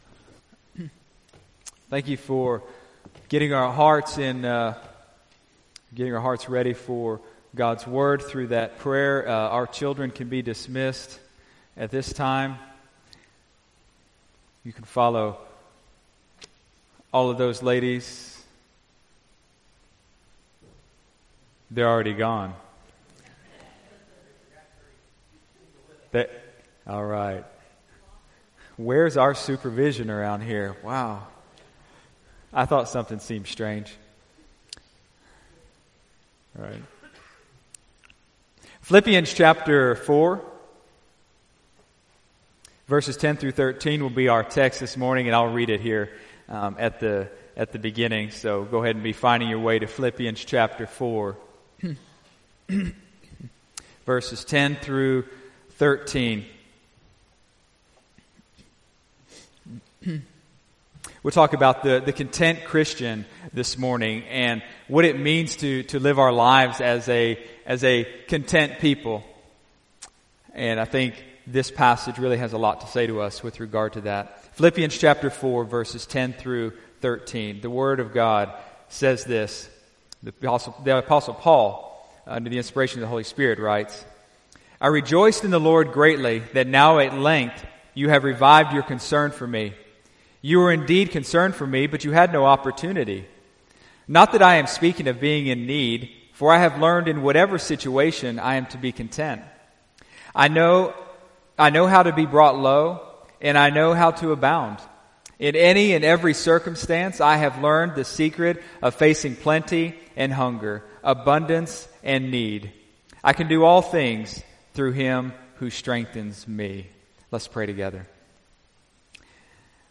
Sunday, October 28, 2018 (Sunday Morning Service)